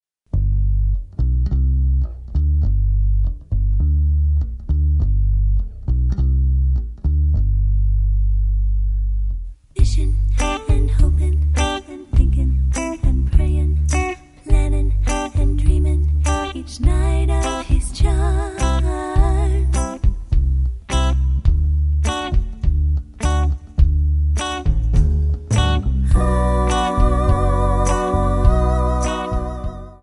MPEG 1 Layer 3 (Stereo)
Backing track Karaoke
Pop, Oldies, 1960s